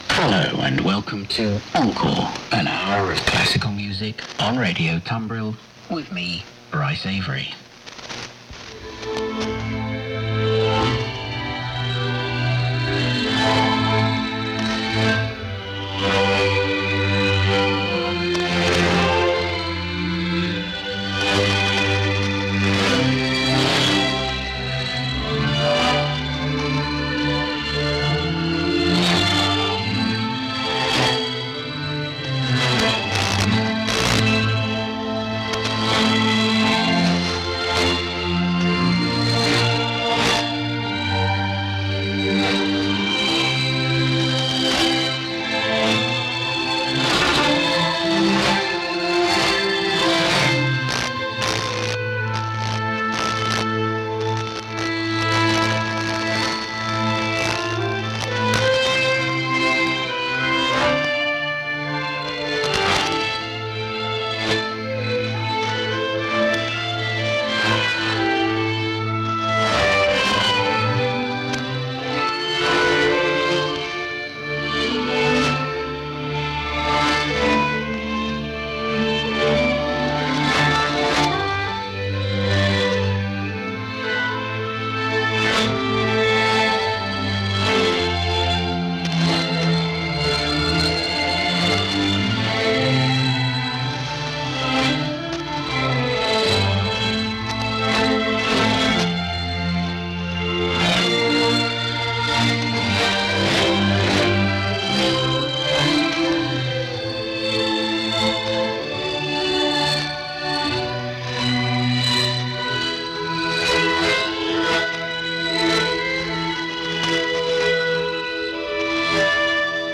Radioescucha: Programa Encore, música clásica por Onda Corta
En este caso se transmite por WRMI (Radio Miami Internacional).
Frecuencia: 5010 Khz Radio receptor Kenwood R-600 Antena hilo largo, 10 metros de longitud a una altura de 6 metros.